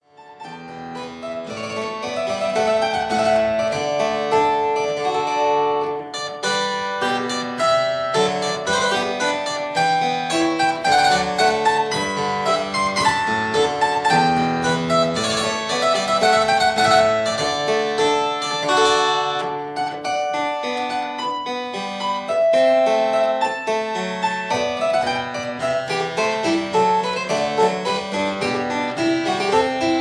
two manual harpsichord